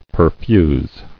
[per·fuse]